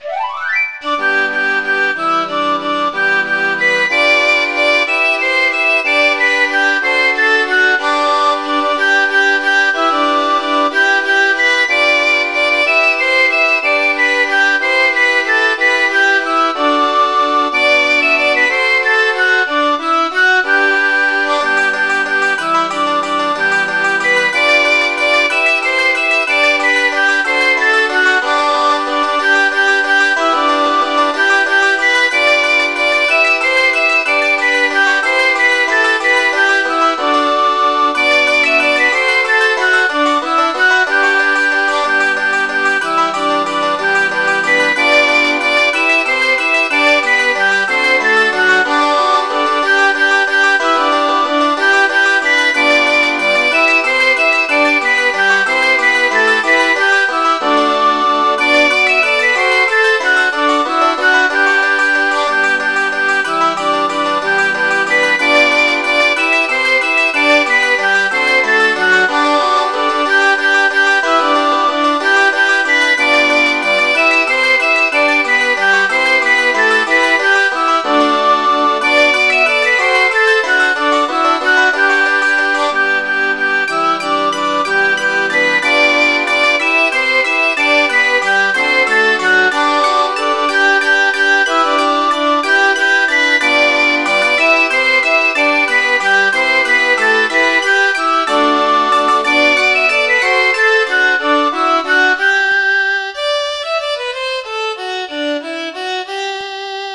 qbrye.mid.ogg